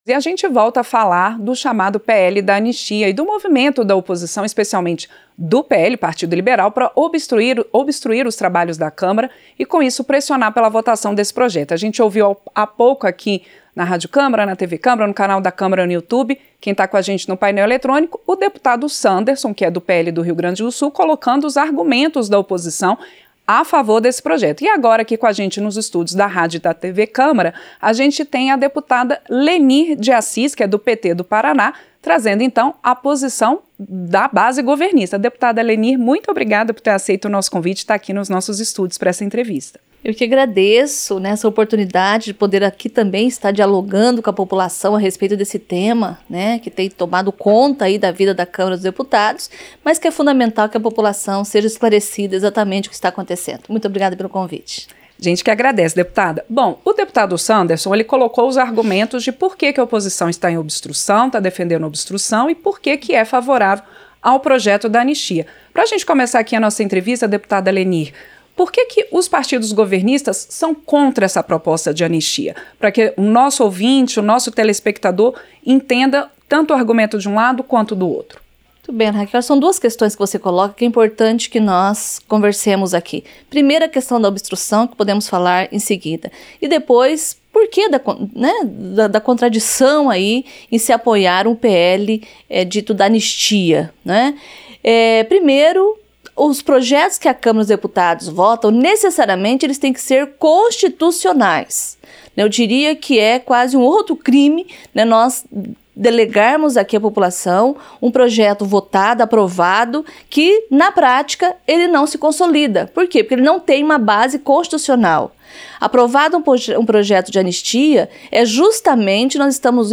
A deputada Lenir de Assis (PT-PR) criticou a obstrução feita pela oposição por conta do chamado PL da Anistia. Em entrevista ao Painel Eletrônico, a parlamentar governista disse que considera uma irresponsabilidade a obstrução de pautas importantes por um projeto que, na avaliação dela, é inconstitucional.
Entrevista - Dep. Lenir de Assis (PT-PR)